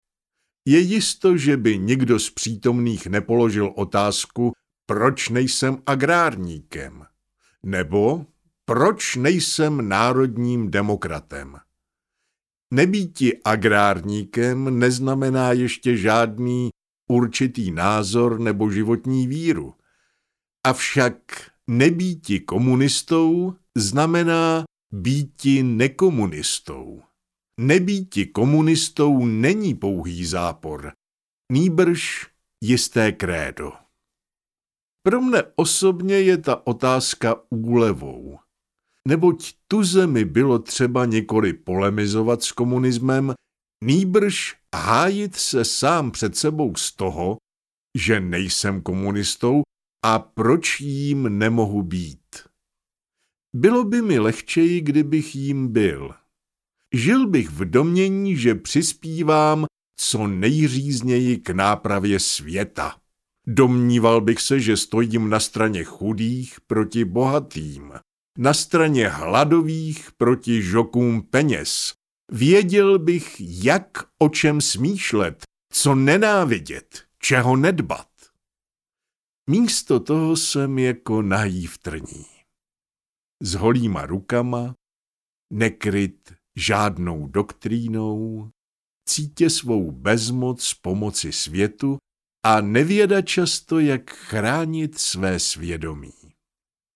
Proč nejsem komunistou I. - Josef Čapek, Jan Herben, Josef Hora, Karel Čapek, Jaroslav Kallab, Richard Weiner, Stanislav Kostka Neumann - Audiokniha